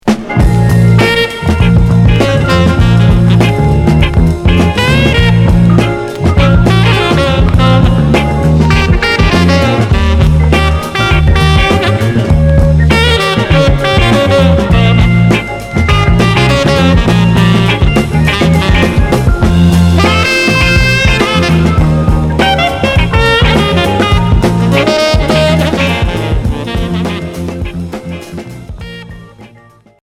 Groove